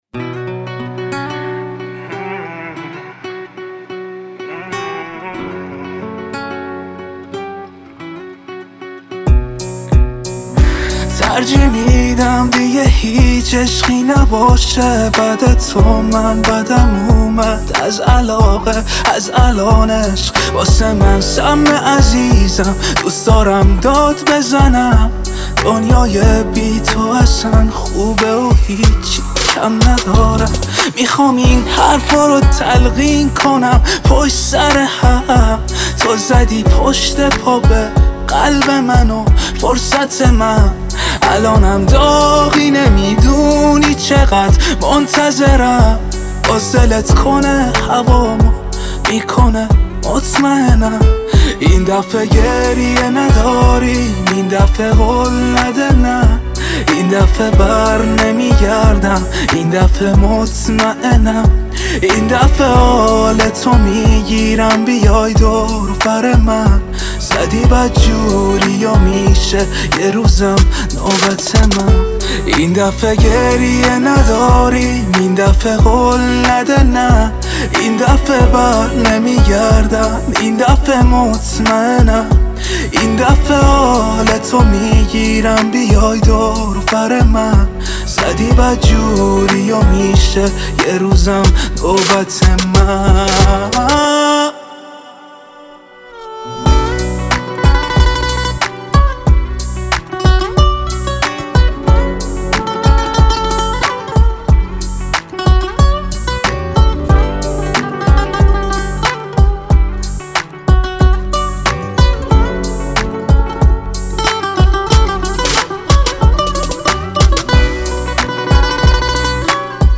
emotional new song